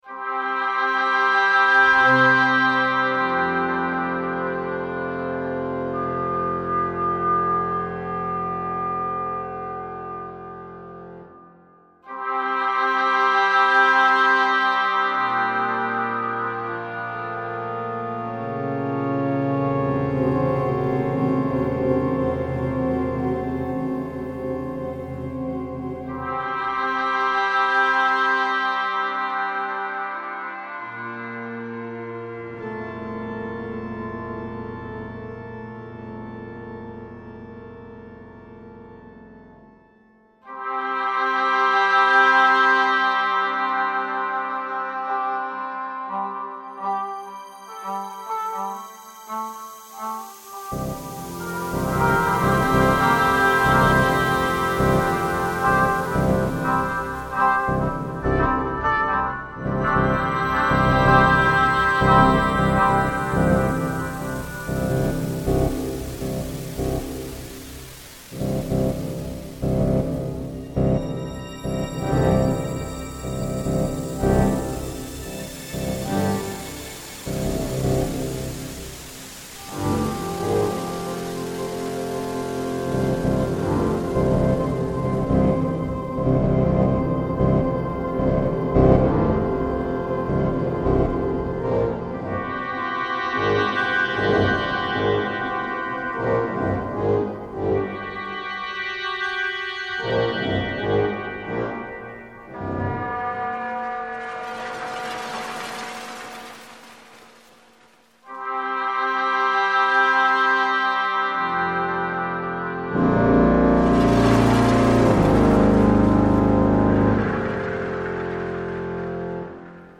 för elektronisk bandstämma, tenor och kör, år 2007
Ouvertyr :